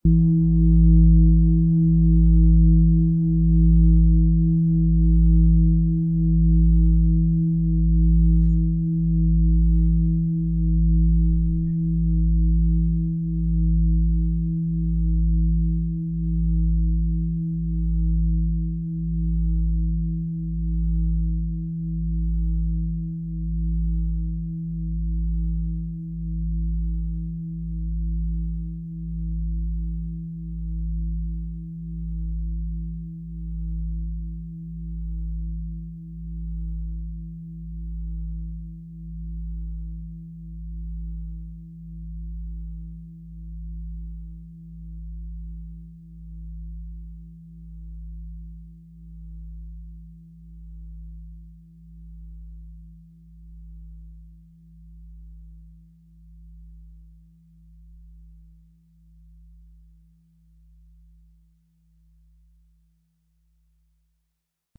XXXL Fußreflexzonenschale
Ihr kraftvoller, harmonischer Klang hüllt dich ein, berührt dein Herz und schafft einen Raum der Geborgenheit.
Der Hopi-Herzton ist eine sanfte und gleichzeitig tiefgehende Frequenz, die mit dem Herzchakra in Resonanz steht.
Um den Original-Klang genau dieser Schale zu hören, lassen Sie bitte den hinterlegten Sound abspielen.